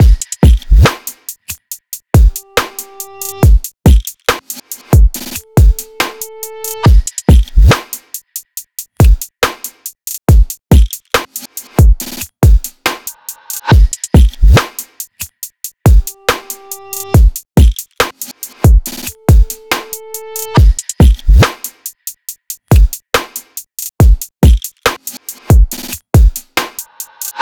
ただ、まだ何か寂しいなーと思いましたので、ギターの音を逆再生した音を入れてみました。